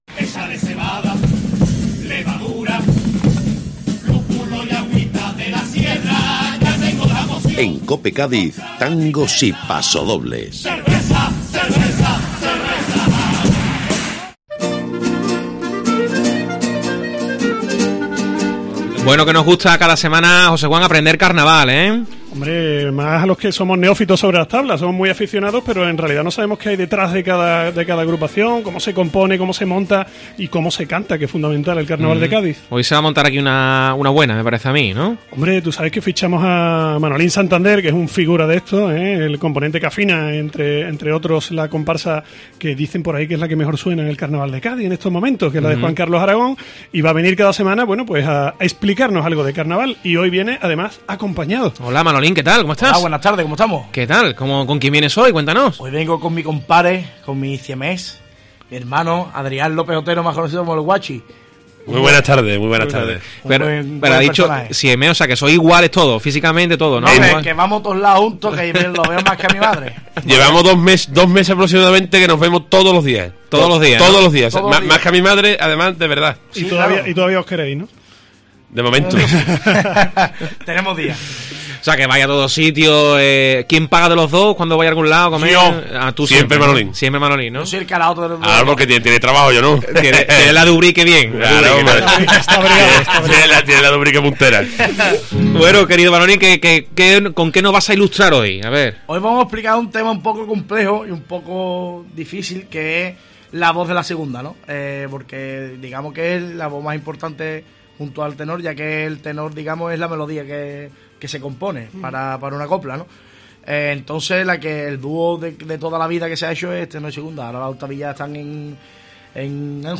No te pierdas los pasodobles que nos cantan en COPE